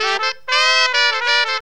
HORN RIFF 18.wav